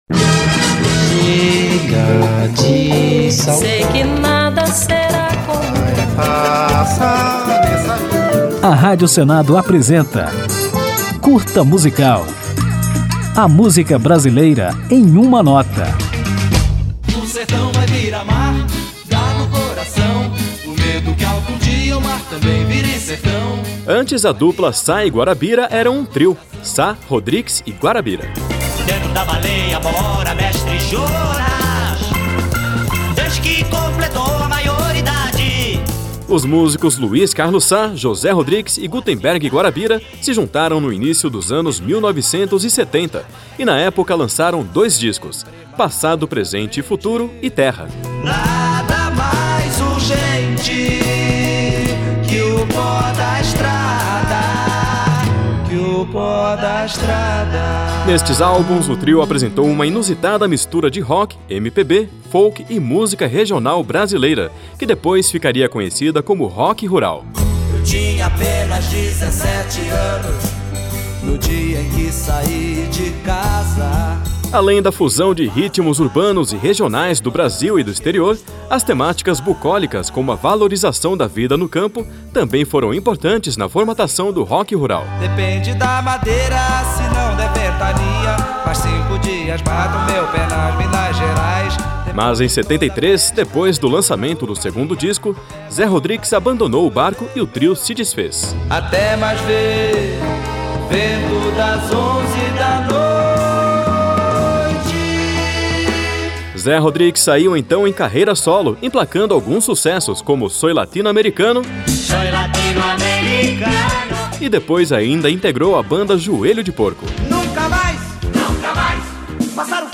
Neste Curta Musical você confere um pouco da história do trio e ainda ouve Mestre Jonas, um dos grandes clássicos de Sá, Rodrix e Guarabyra.